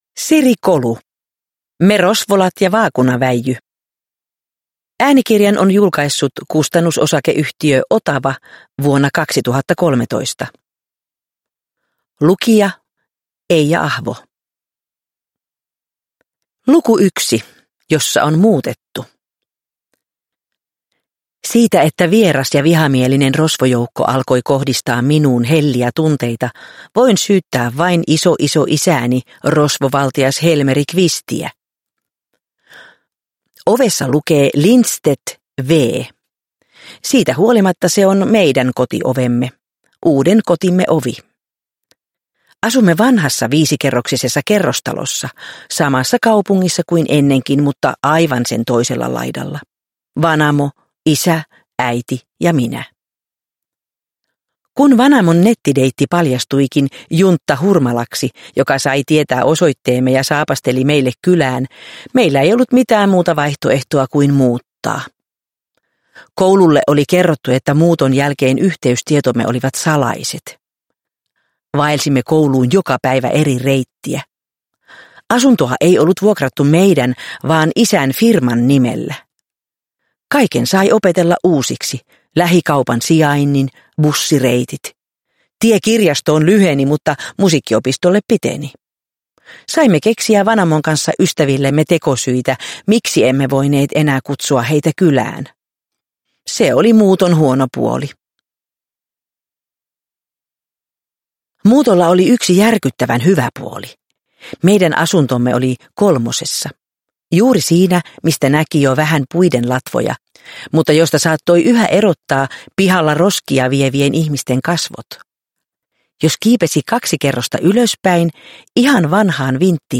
Me Rosvolat ja vaakunaväijy – Ljudbok – Laddas ner